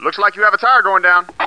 1 channel
BADTIRE1.mp3